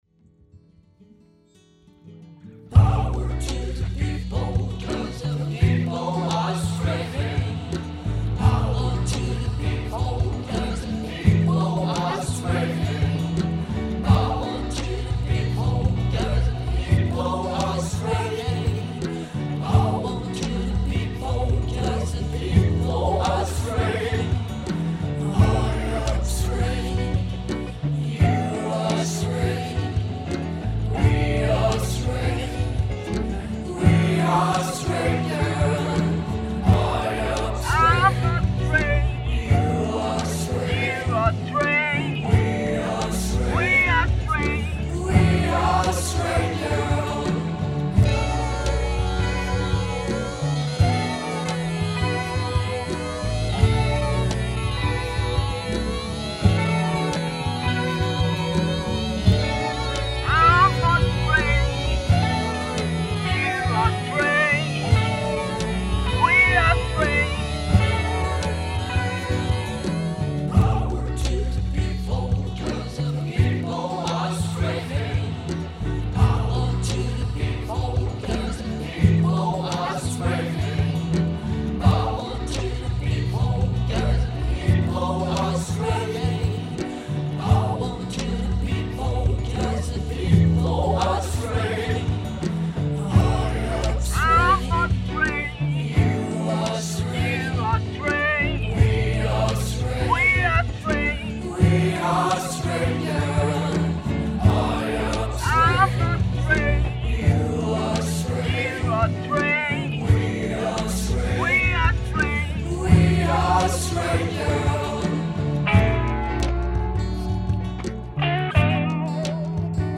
Psychedelic rock